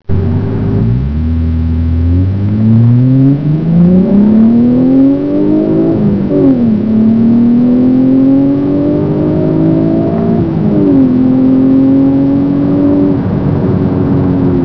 The sound is much more quiet at idle yet loud enough under hard acceleration. I have never experienced any amount of droning, to date.